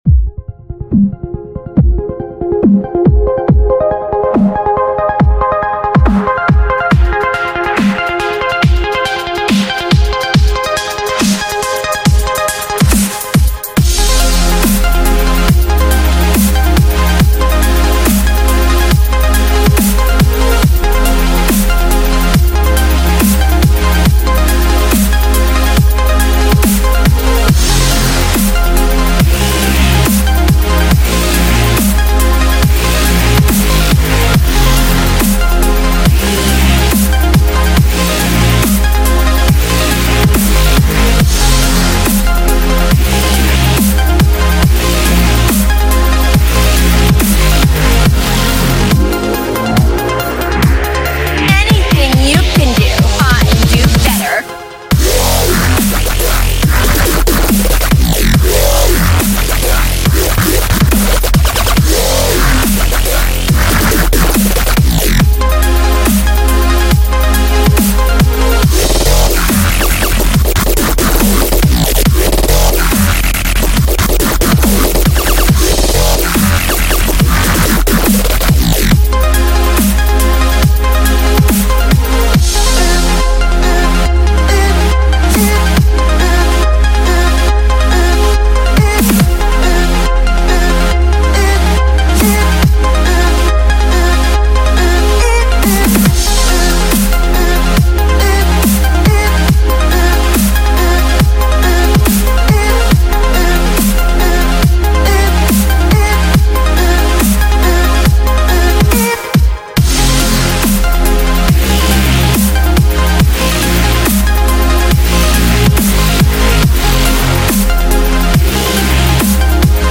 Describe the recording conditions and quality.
This was done in about 6-7 hours in FLStudio.